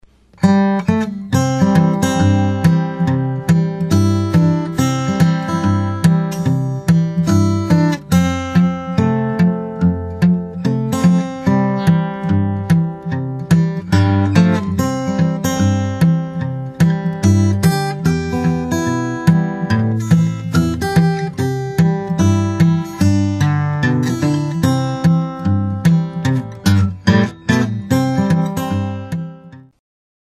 solo arrangement (3 times through with variations).